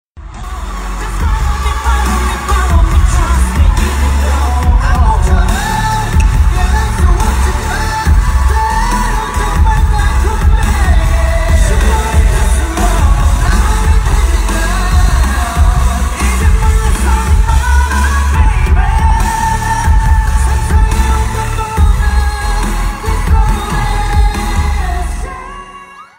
club
vocal
live version
Песня с лайва.